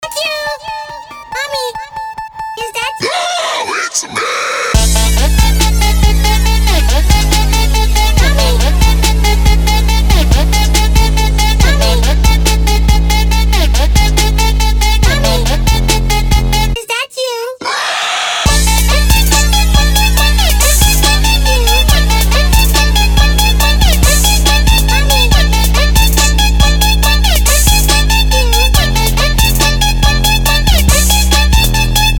• Качество: 320, Stereo
club